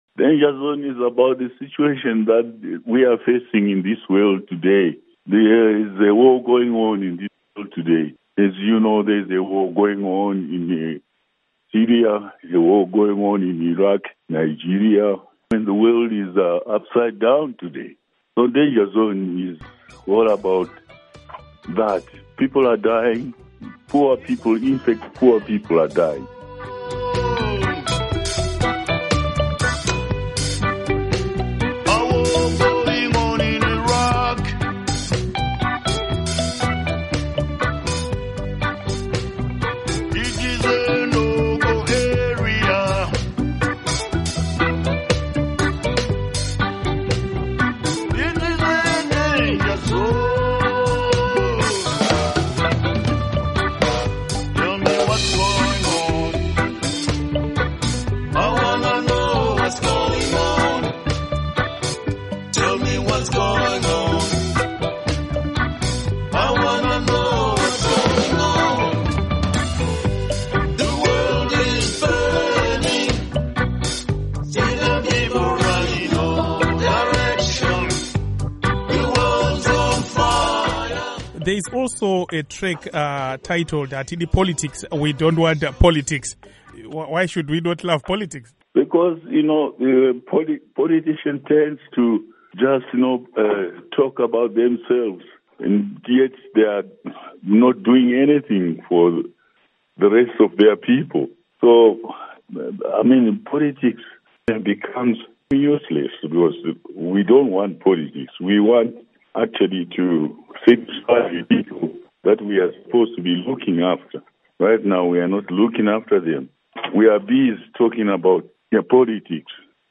Interview With Thomas Mapfumo